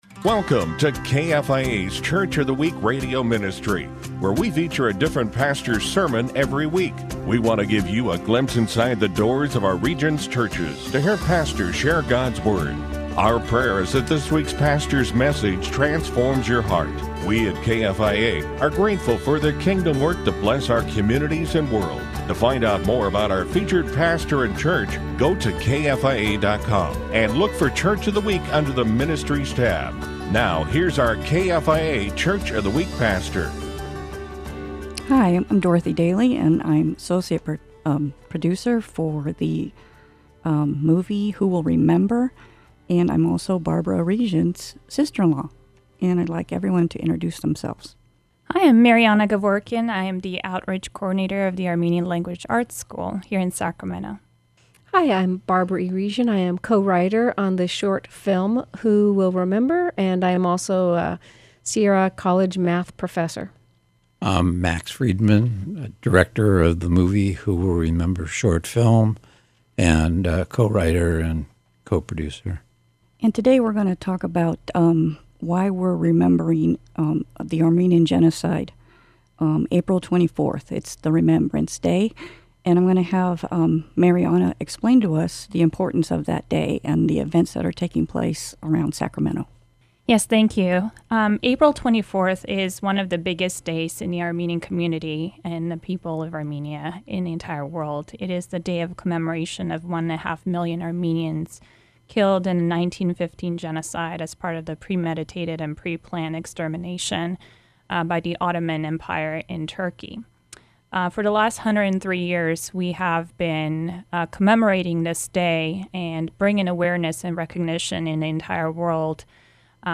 PART 2 : Listen to KFIA’s Pastor to Pastor Interview.